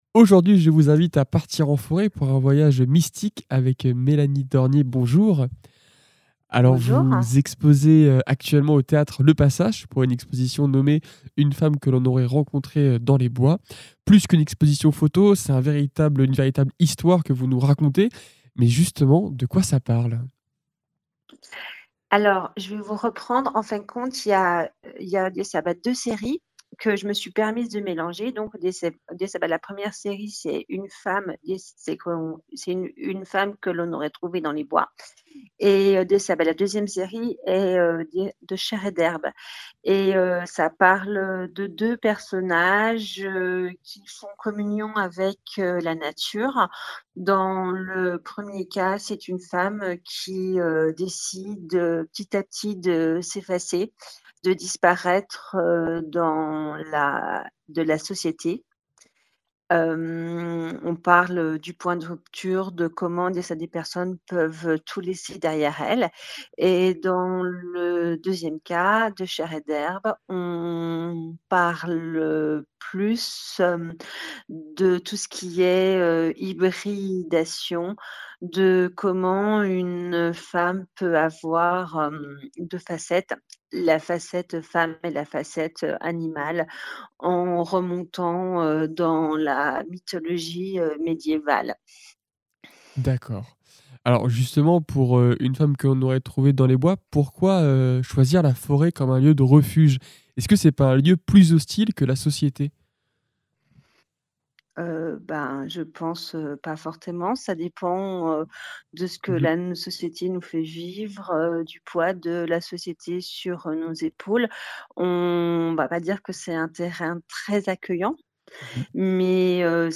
Les interviews Radar Actu Interview fécamp podcast